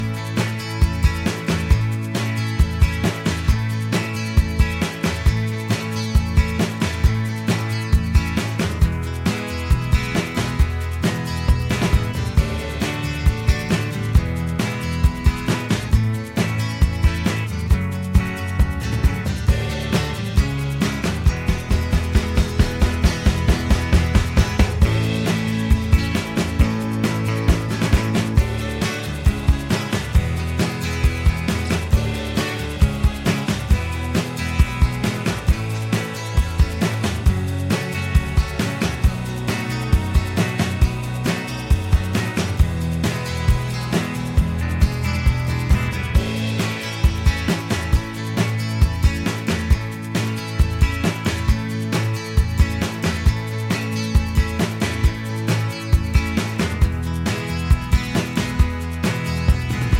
Minus Main Guitars For Guitarists 2:25 Buy £1.50